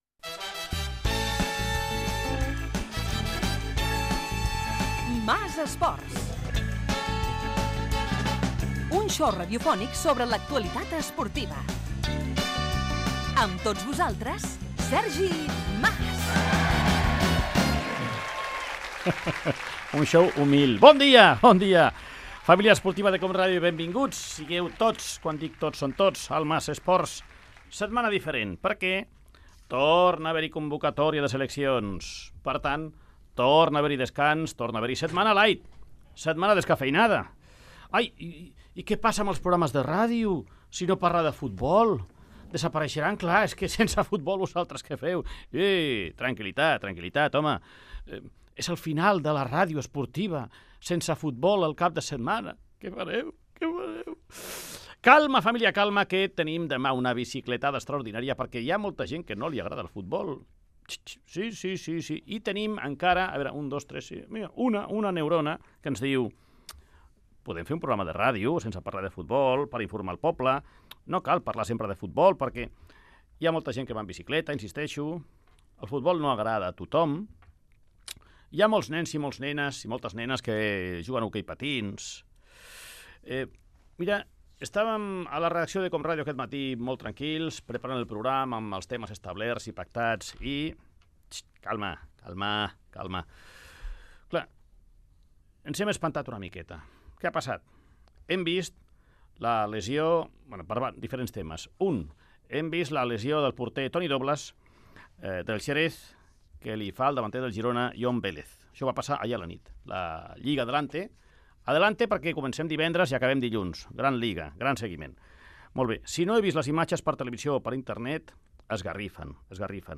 Careta, salutació, comentari de l'actualitat esportiva, titulars
Esportiu